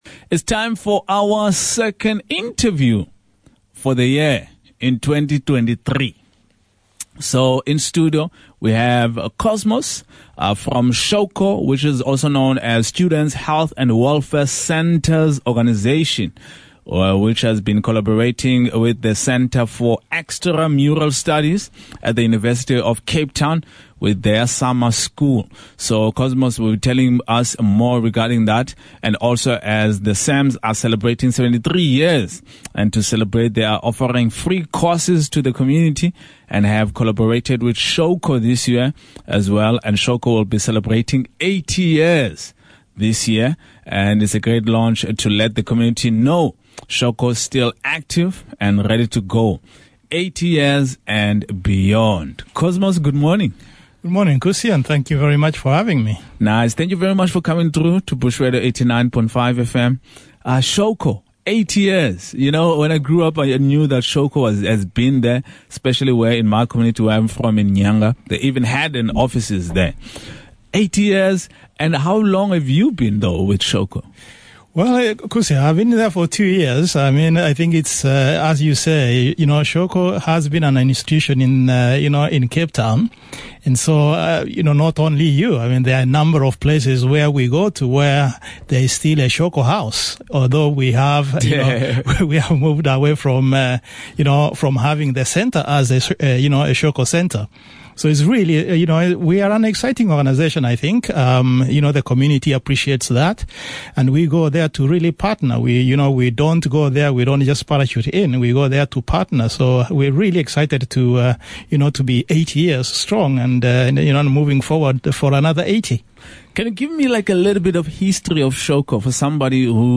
Listen to radio interview with Shawco and Bush Radio.